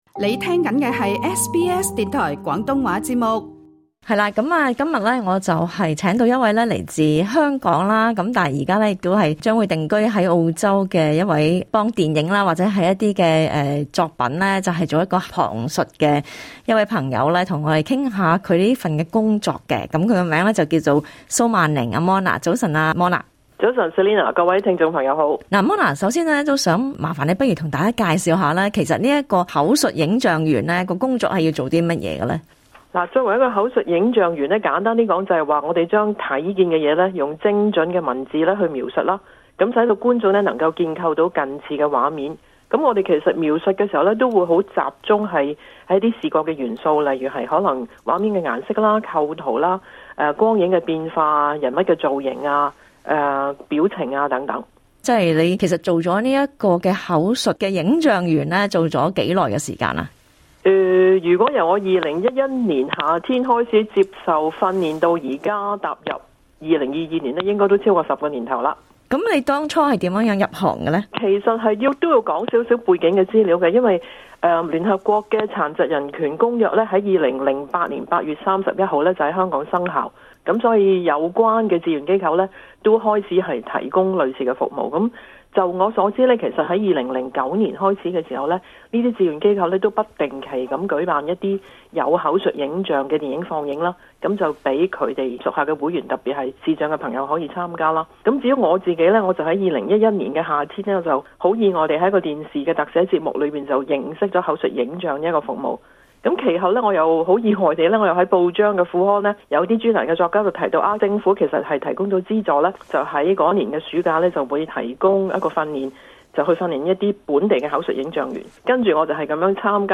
社區專訪